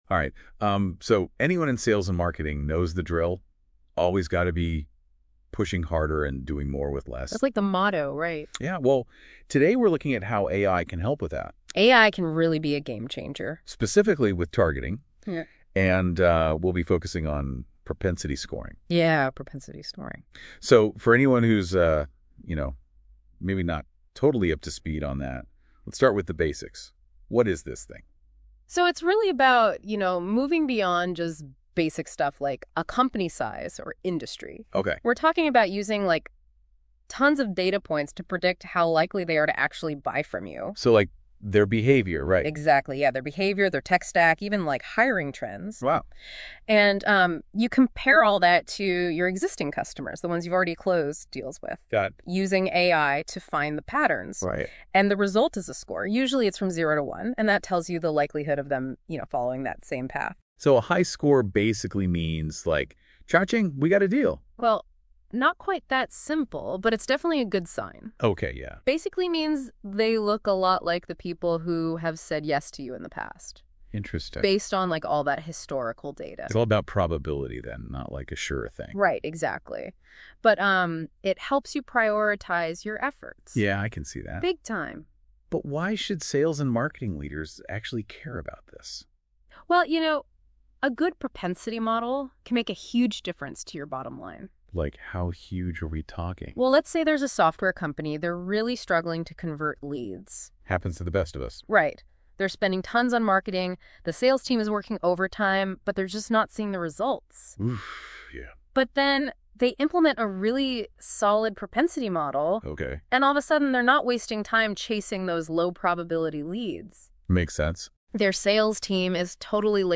This podcast episode was generated using AI combined with original ideas and content from the G2M Insights blog.